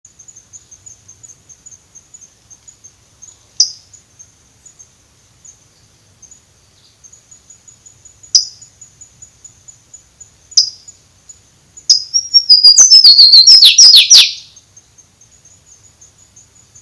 Este haciendo el canto típico.
Life Stage: Adult
Condition: Wild
Certainty: Photographed, Recorded vocal